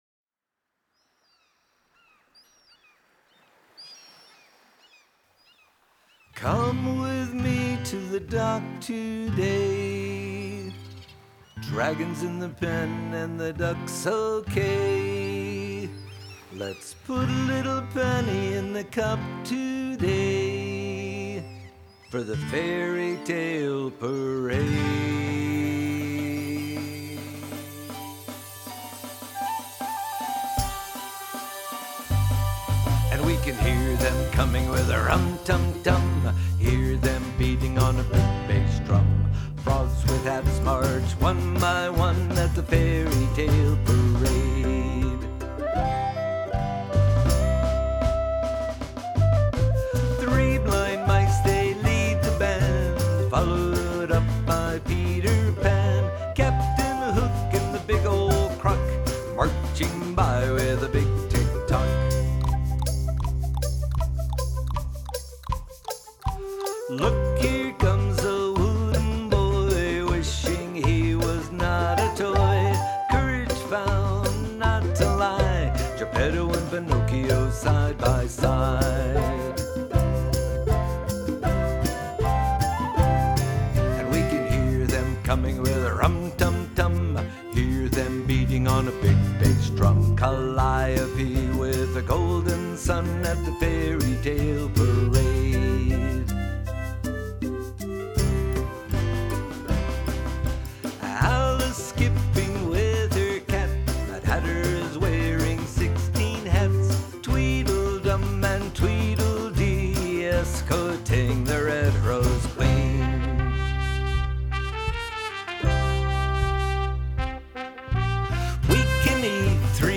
Guitar and vocals
Whistle
Drums and percussion